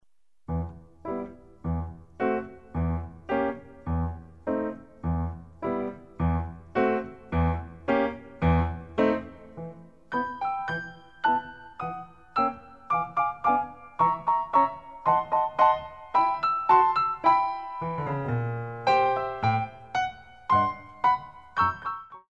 Ballet CD